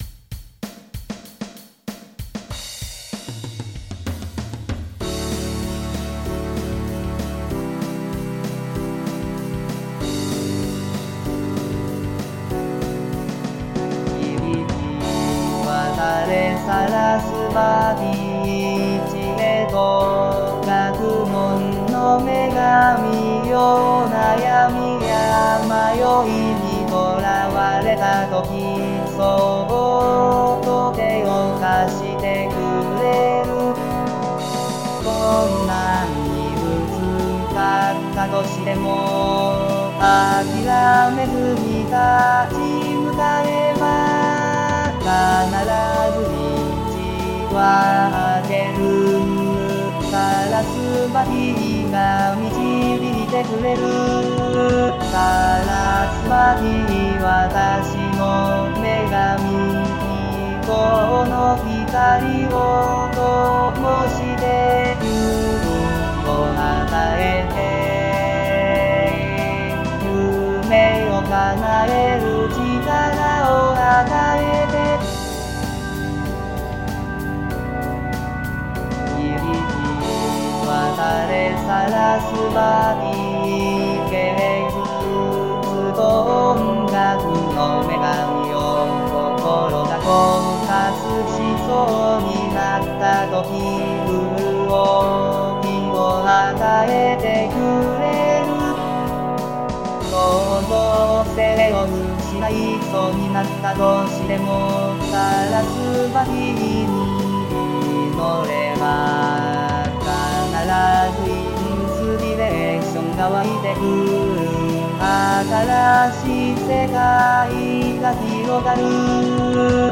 日本語歌詞からAI作曲し、伴奏つき合成音声で最長10分歌います。